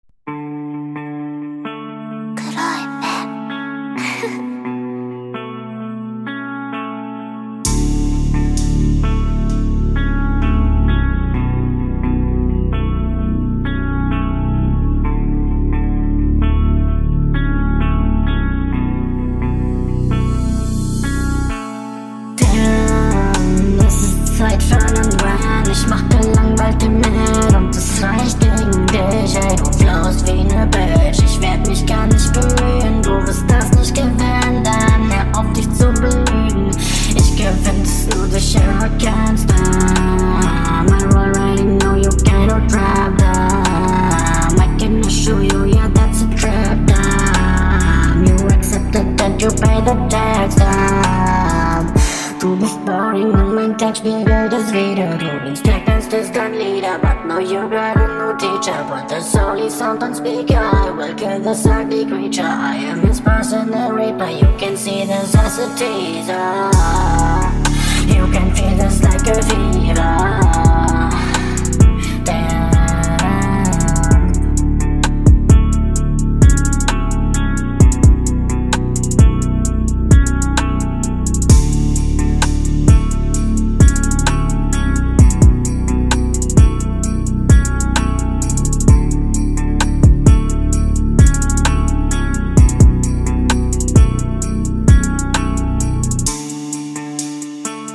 Der Beat ist eigentlich ziemlich cool am Anfang, vor allem wenn gerade die Sonne scheint …